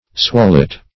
Search Result for " swallet" : The Collaborative International Dictionary of English v.0.48: Swallet \Swal"let\, n. [Cf. G. schwall a sea swell, from schwellen to swell, E. swell.]